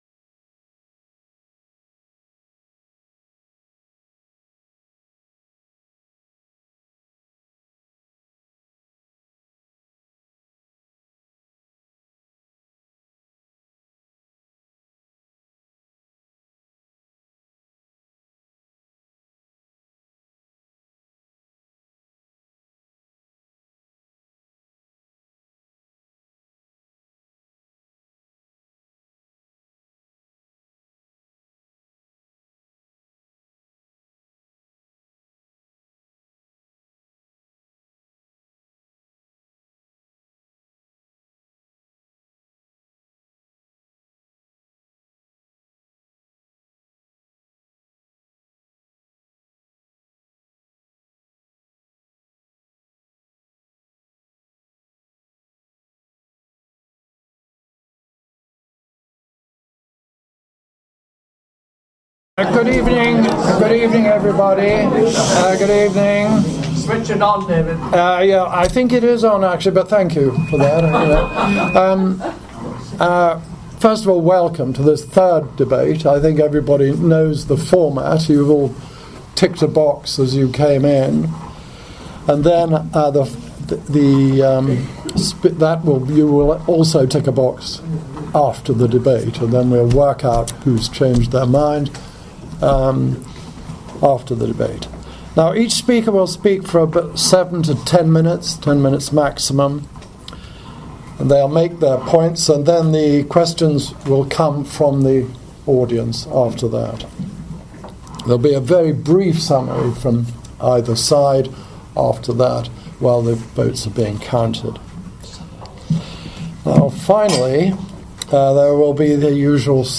BIG DEBATE： IS SHARIA FAIRER than ENGLISH LAW？ Judge & QC debate Muslim Speakers.mp3